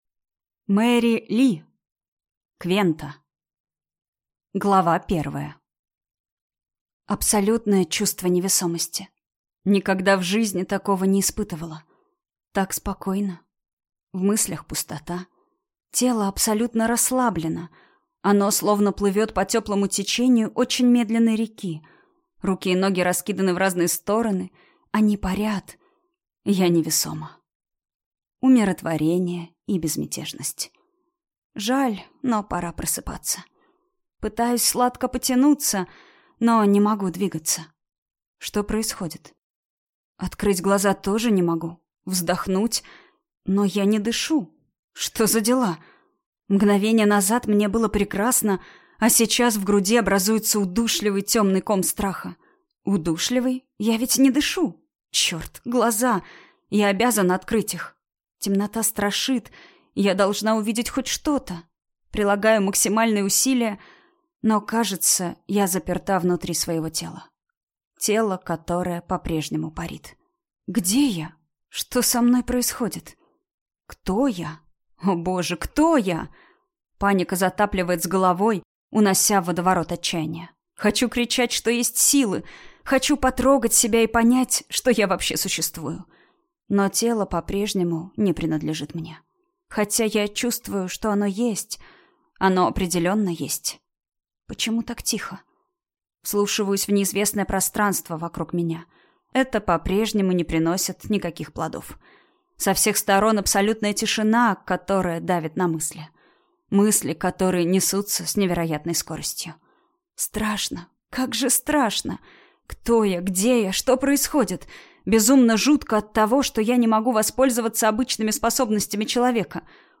Аудиокнига Квента | Библиотека аудиокниг